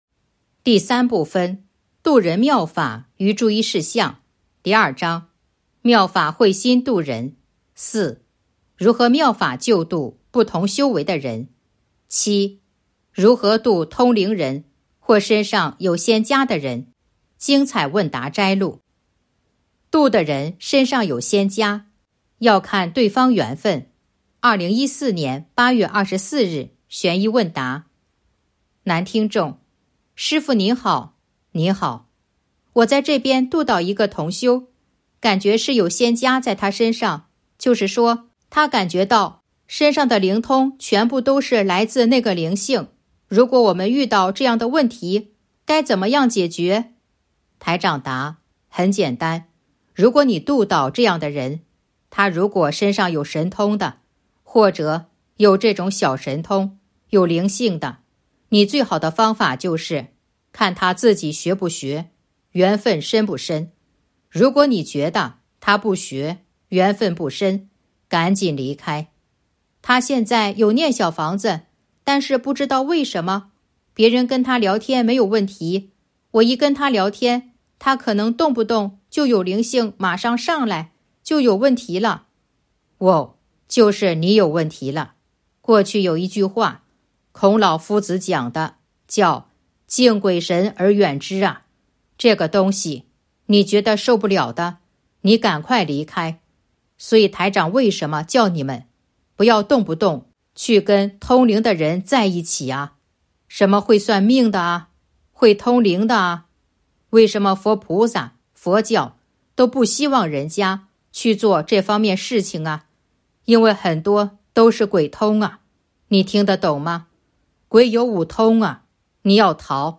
034.（七）如何度通灵人或身上有仙家的人精彩问答摘录《弘法度人手册》【有声书】